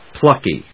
pluck・y /plˈʌki/